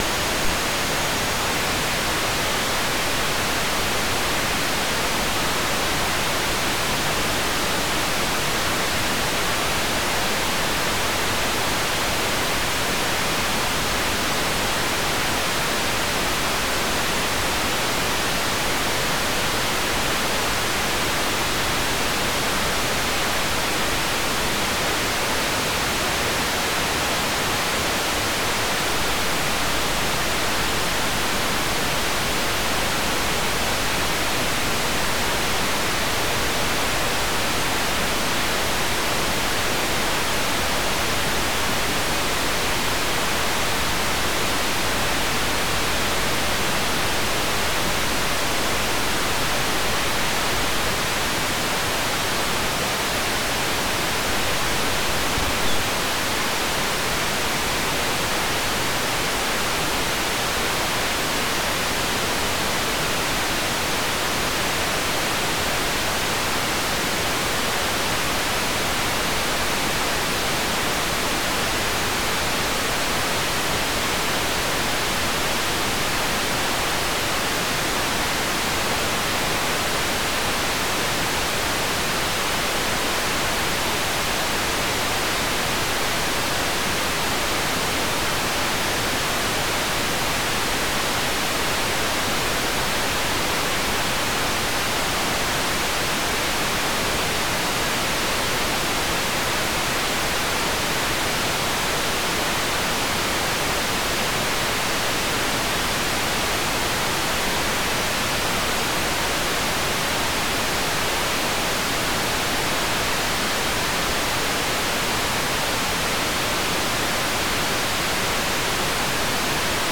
"transmitter_description": "Mode V - SSTV - Martin M1 SSTV",